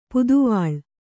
♪ puduvāḷ